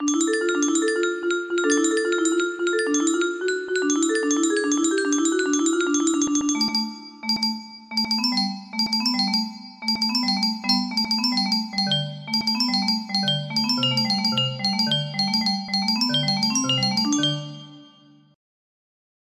Music kinda music box melody